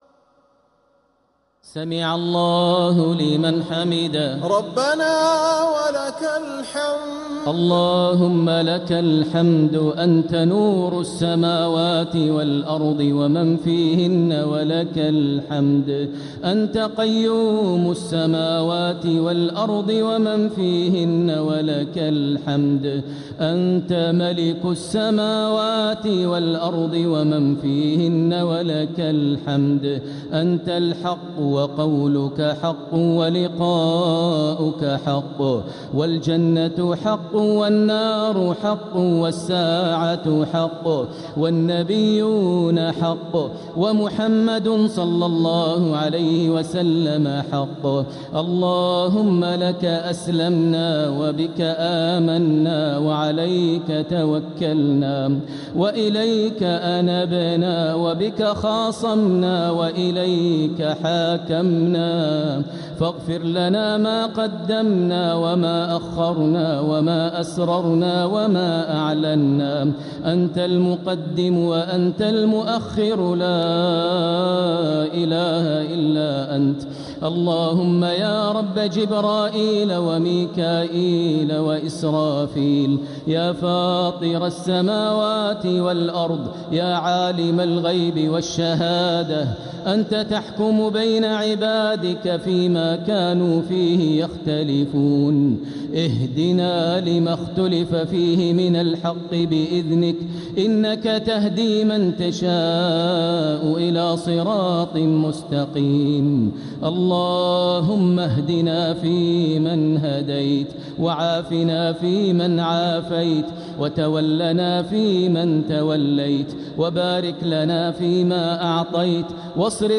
دعاء القنوت ليلة 15 رمضان 1447هـ | Dua for the night of 15 Ramadan 1447H > تراويح الحرم المكي عام 1447 🕋 > التراويح - تلاوات الحرمين